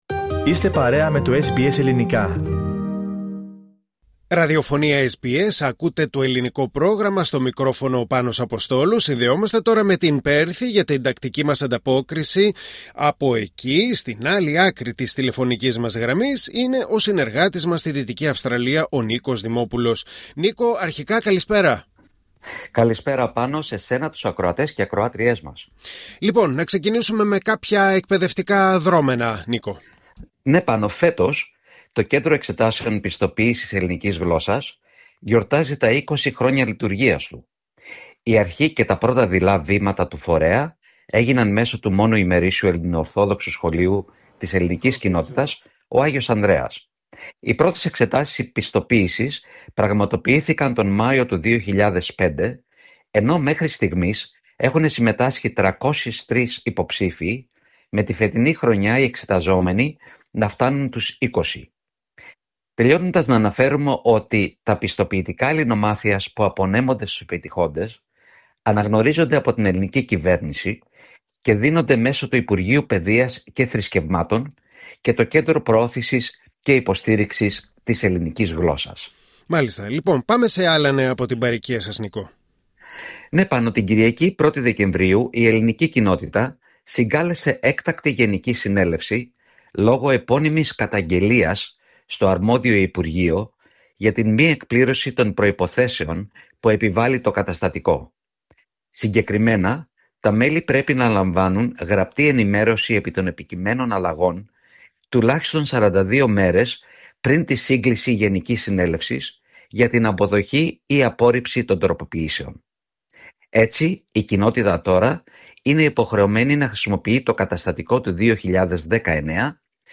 Ακούστε την εβδομαδιαία ανταπόκριση από την Δυτική Αυστραλία